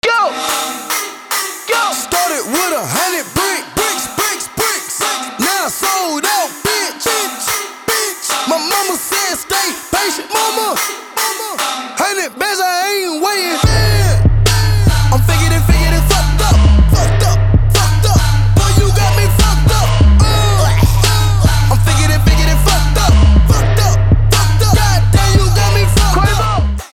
Trap
Rap